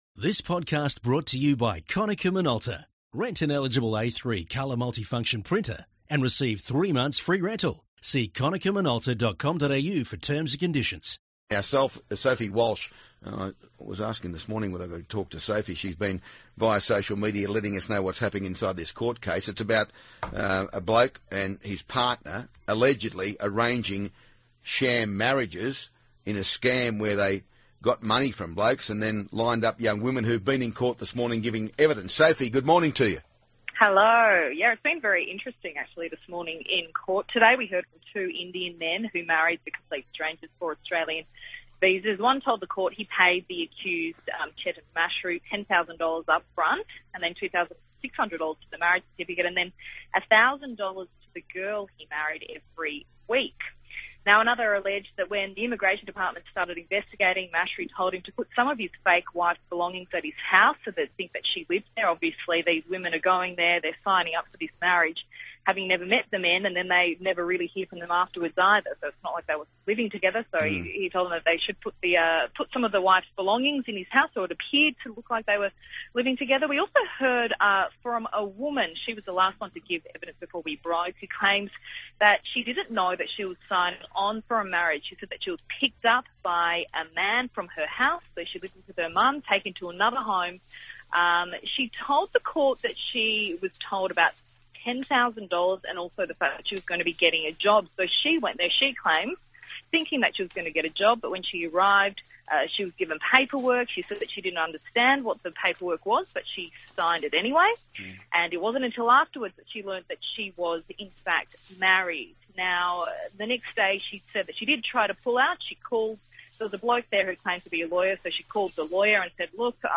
talks to Ray from Brisbane Magistrate’s Court about the Indian sham marriage scam